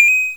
Player Collect Coin.wav